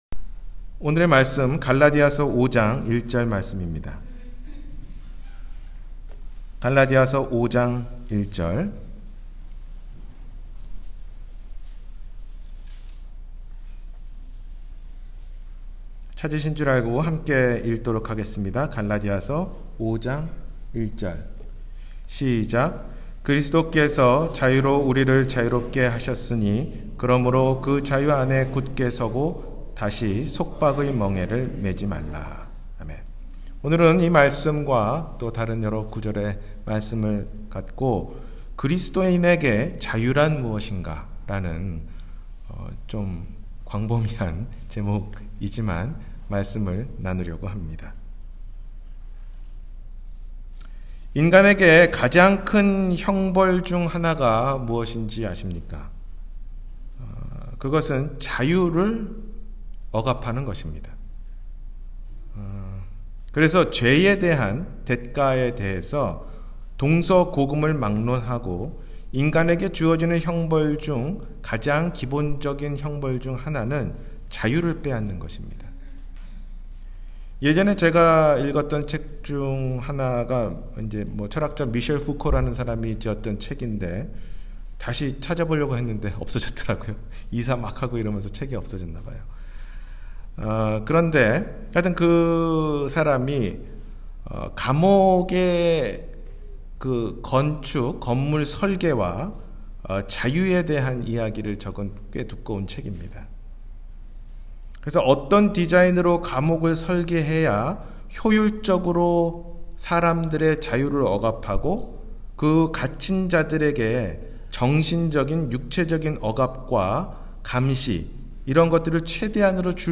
그리스도인에게 자유란 무엇인가?-주일설교